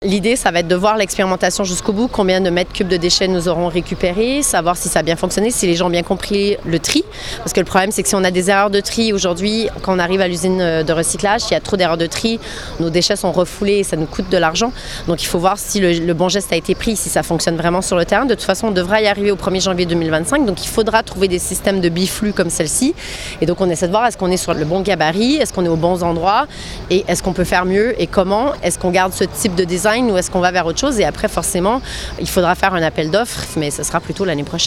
Les explications de Chantal Farmer, maire déléguée de la commune d’Annecy.